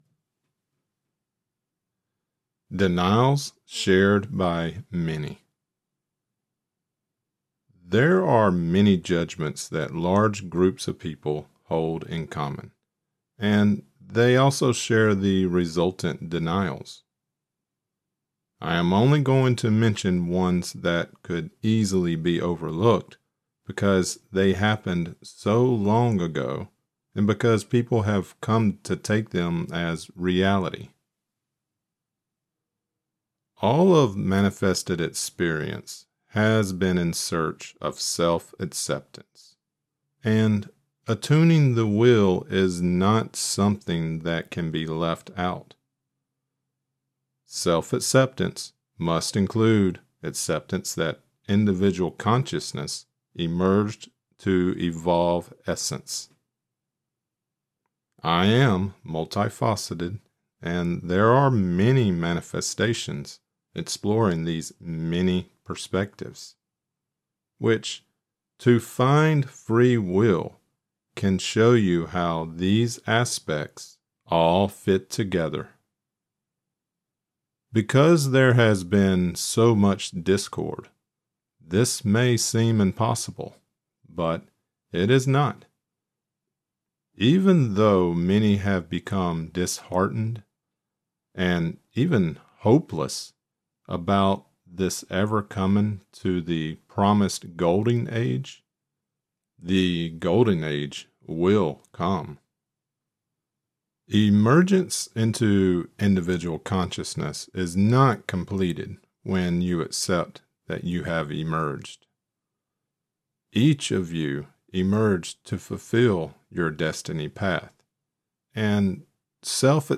This is part 16 of the Right Use of Will and this lecture includes: Denials Shared By Many There are eight sections in part 16: * Attuning the Will * The Beginning of Death * Entrapment on Earth * Remembrance * Destiny Path * Recovery of Lost Will * Judgments * Escape Death Lecture Created Transcript Blockchain Denials Shared By Many 12/16/2025 Denials Shared By Many (audio only) 12/16/2025 Watch Right Use of Will part 16 lecture: Right Use of Will lectures are also located on the Cosmic Repository video site .